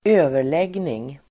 Uttal: [²'ö:ver_leg:ning]